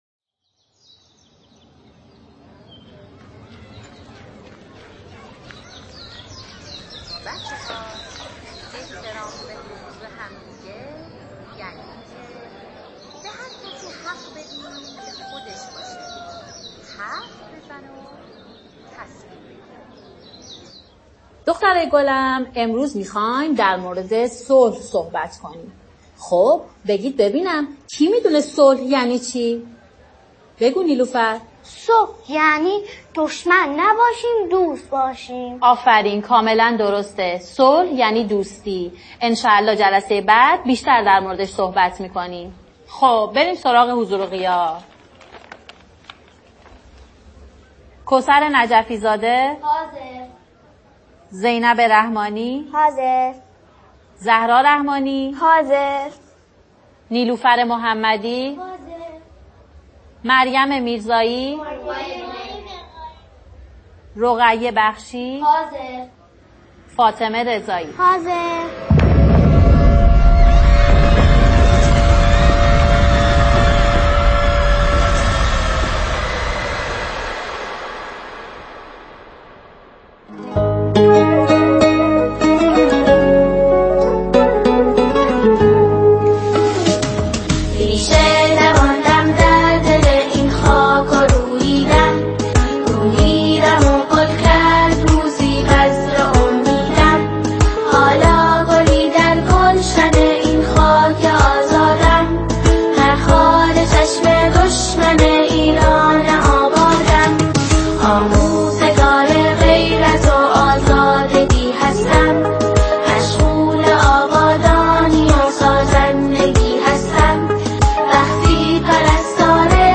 ژانر: سرود ، سرود انقلابی ، سرود مناسبتی